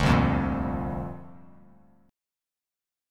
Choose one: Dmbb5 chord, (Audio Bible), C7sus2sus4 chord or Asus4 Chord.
C7sus2sus4 chord